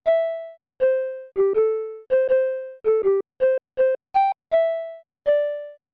Sound effect from Super Mario RPG: Legend of the Seven Stars
Self-recorded using the debug menu
SMRPG_SFX_Lulla-Bye.mp3